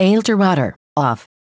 Create sound (voice) files for OpenTX with MacOS’ Siri in less than 1 minute
If you own an Apple Mac or MacBook and want to create your own quality voice sound files using macOS Siri voices – read on.
• Sample Rate: 16 kHz
• Tracks: 1, mono
Small hint: If you add a “,” (comma) in the text, it will create a slight delay between the words.